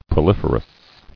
[pro·lif·er·ous]